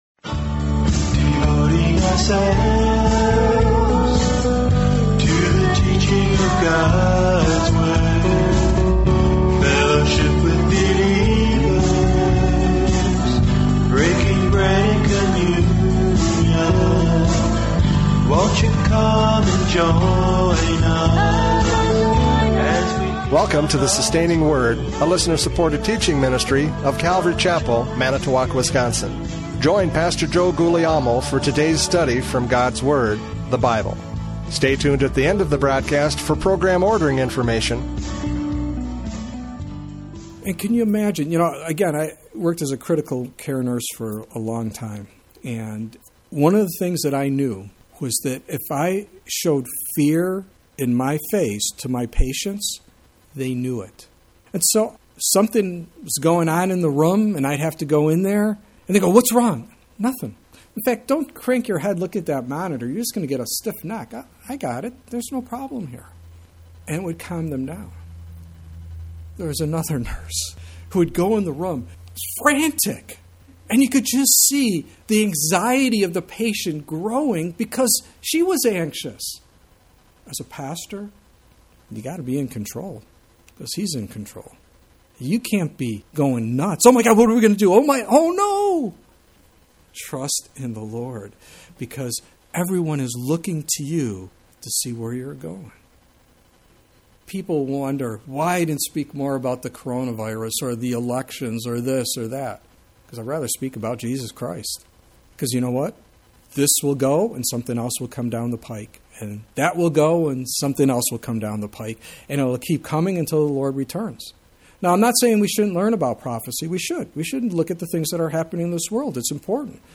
Judges 17:6-13 Service Type: Radio Programs « Judges 17:6-13 Confusion in the Church!